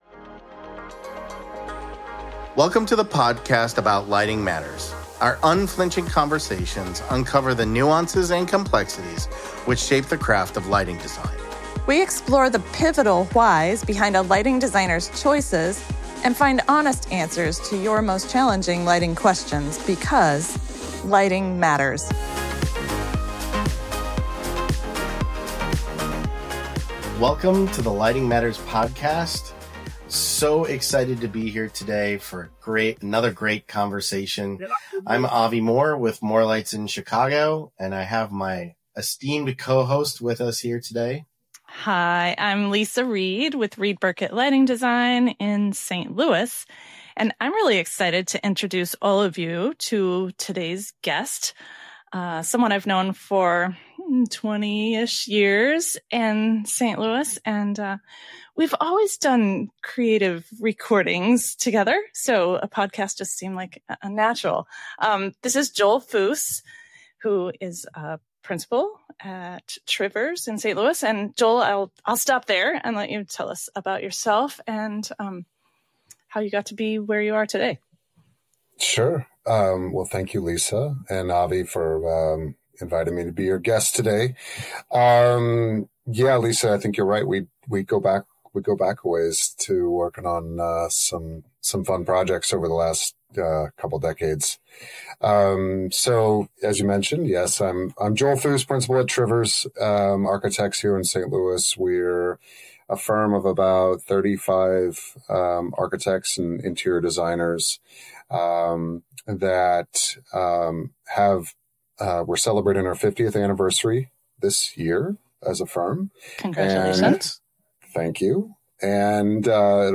The conversation challenges traditional value engineering, reframing it as distillation to a project's essential mission rather than arbitrary cost-cutting.